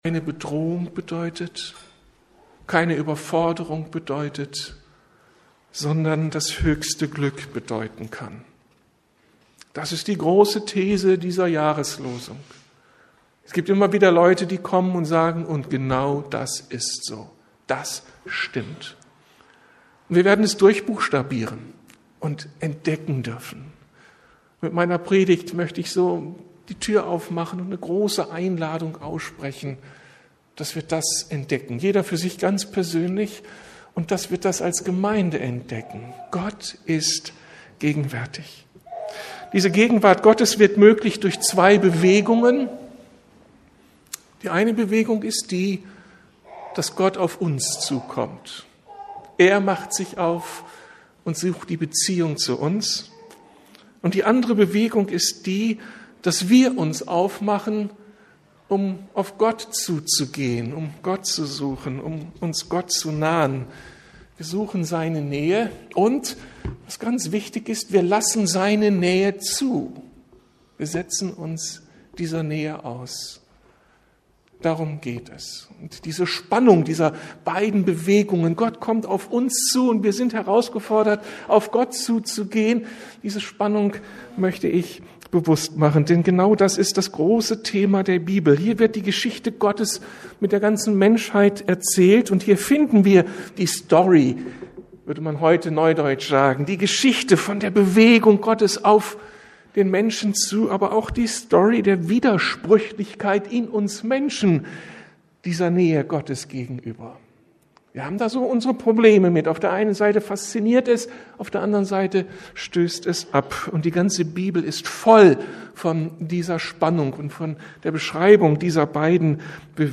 Gott ist gegenwärtig! ~ Predigten der LUKAS GEMEINDE Podcast